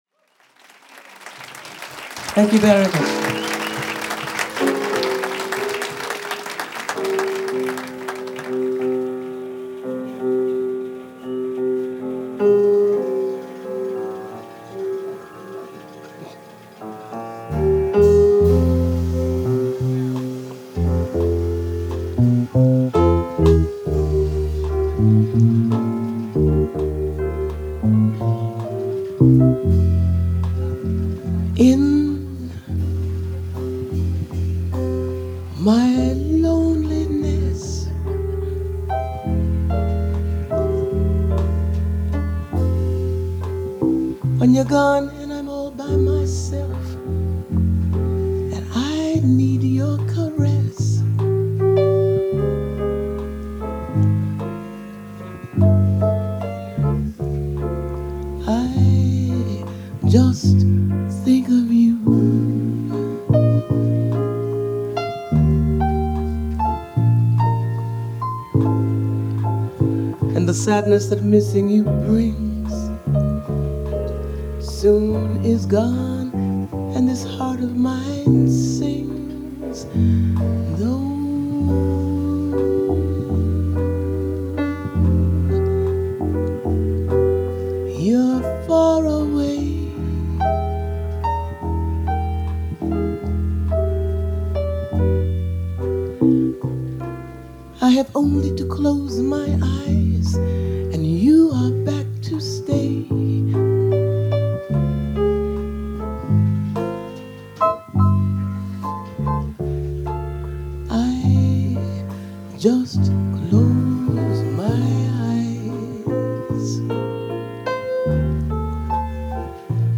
Жанр: Jazz Vocals.